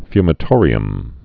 (fymə-tôrē-əm)